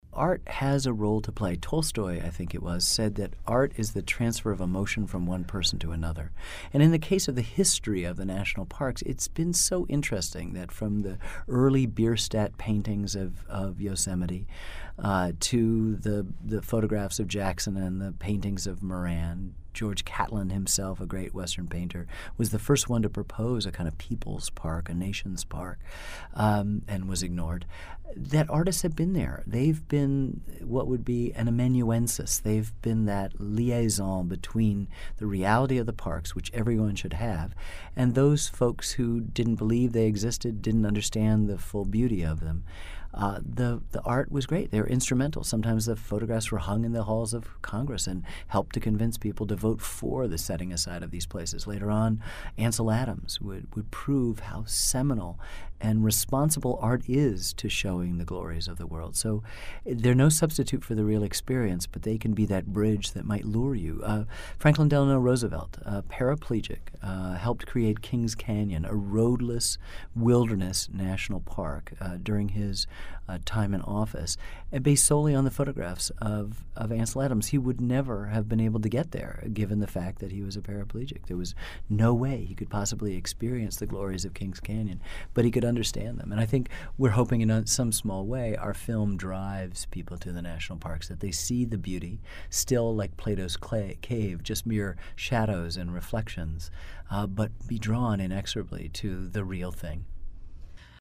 Transcript of conversation with Ken Burns
And to share the glories of the National Parks with others, artists have often been important messengers, as Burns discusses in an excerpt from this week's podcast.